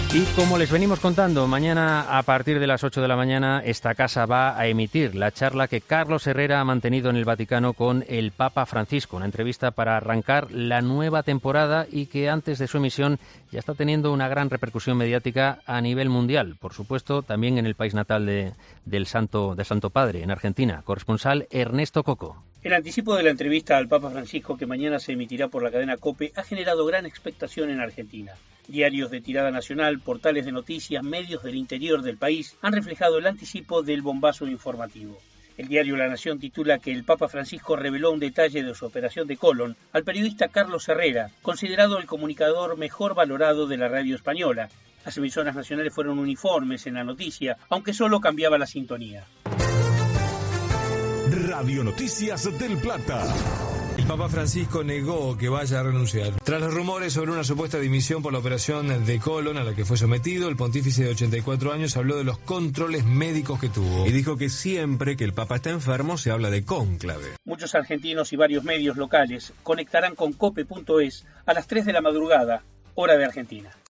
El adelanto de la entrevista de Carlos Herrera al Papa, en los medios de todo el mundo